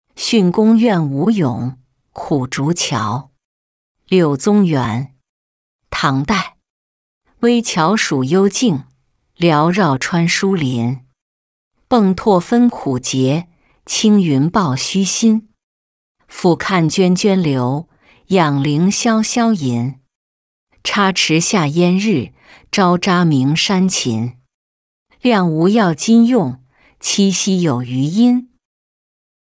巽公院五咏·苦竹桥-音频朗读